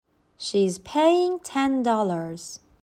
تلفظ با سرعت‌های مختلف